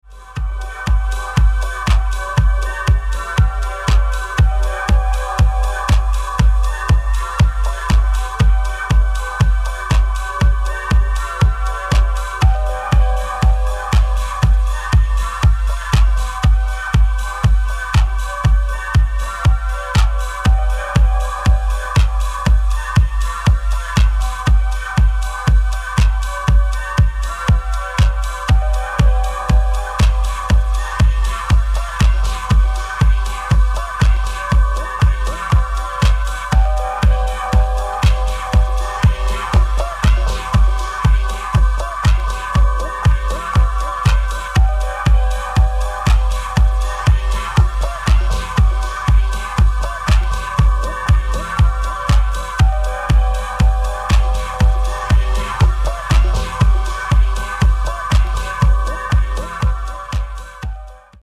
metal-influenced italo-disco
Disco